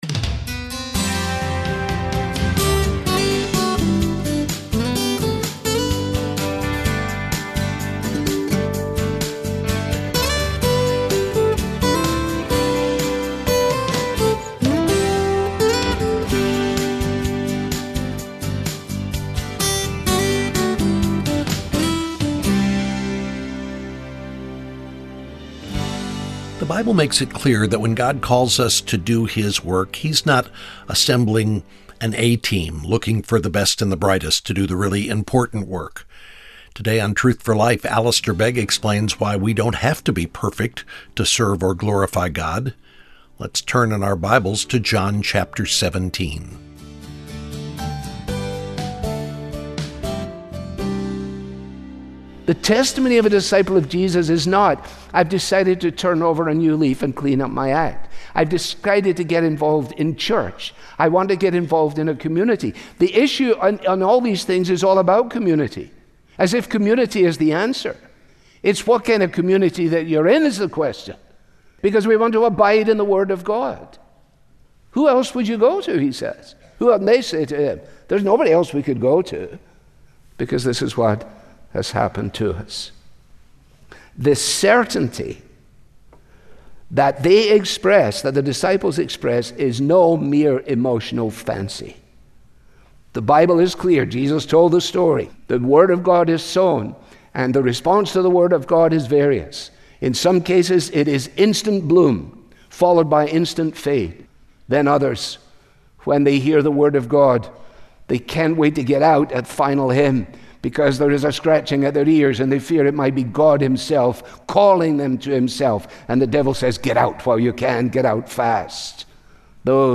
This listener-funded program features the clear, relevant Bible teaching